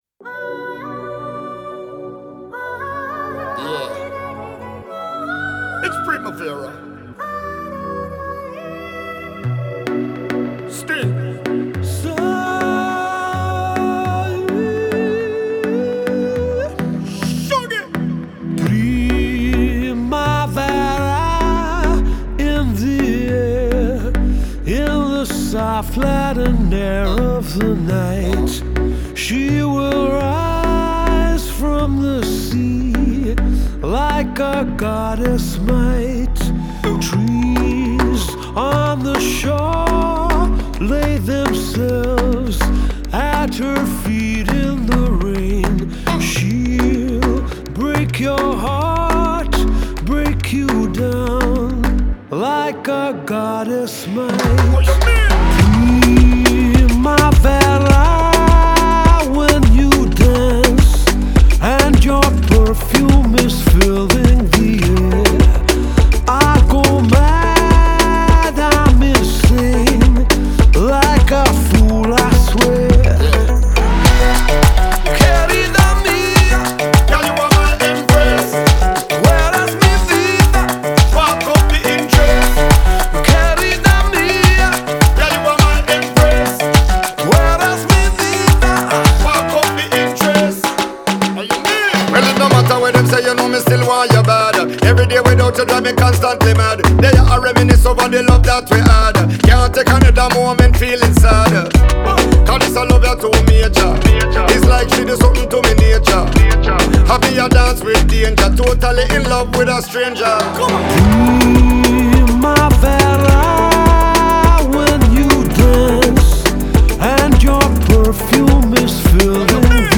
это яркая и мелодичная композиция в жанре регги